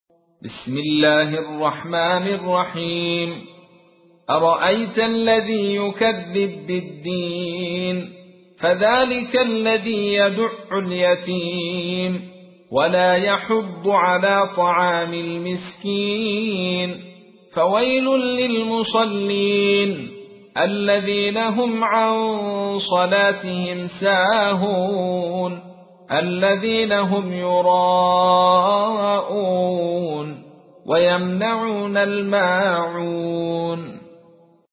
تحميل : 107. سورة الماعون / القارئ عبد الرشيد صوفي / القرآن الكريم / موقع يا حسين